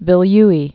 (vĭl-yē)